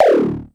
Index of /90_sSampleCDs/300 Drum Machines/Klone Dual-Percussion-Synthesiser/KLONE PITCH NW8
KLONE_DTOM049.wav